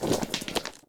mobs_stone_attack.ogg